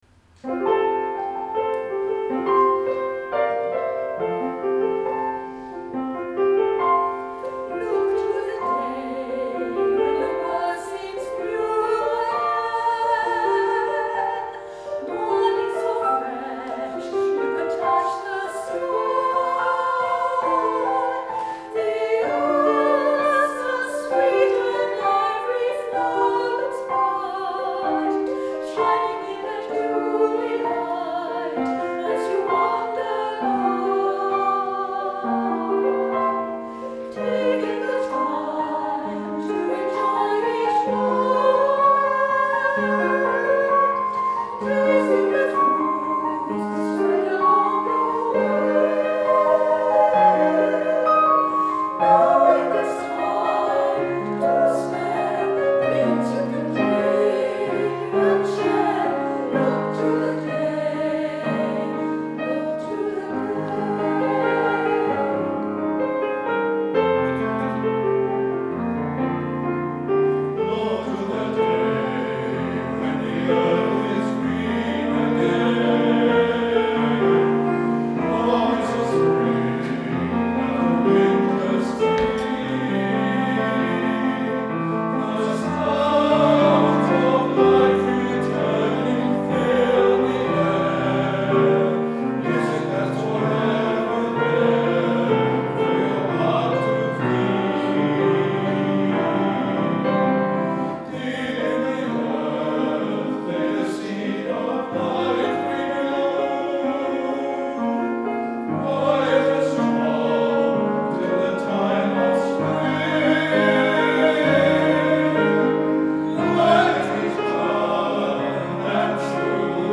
Octet Plus at Summerlea sings for "Ricochet"